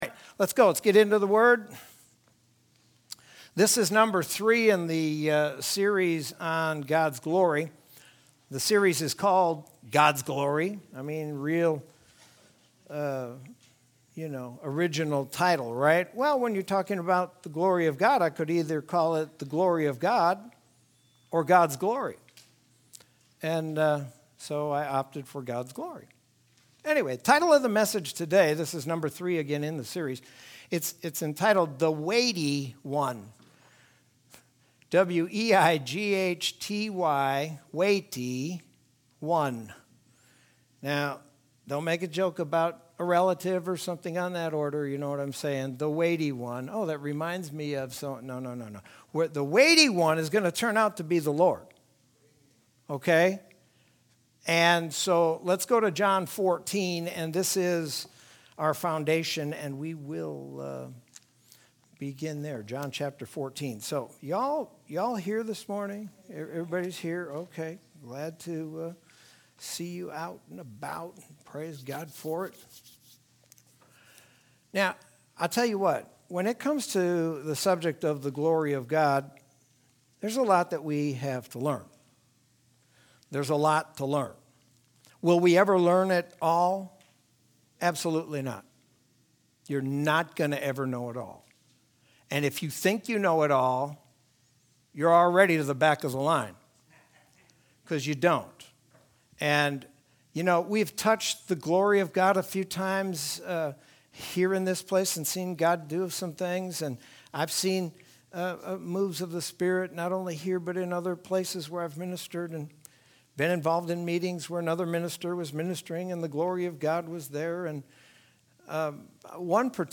Sermon from Sunday, March 7th, 2021.